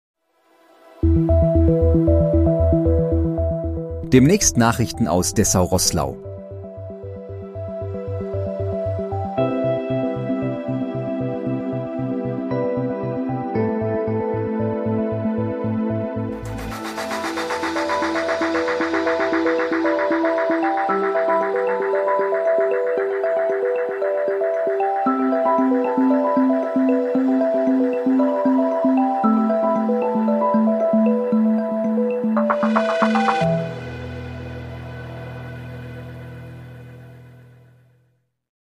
Guten Abend Dessau-Roßlau: Trailer, erstellt mit KI-Unterstützung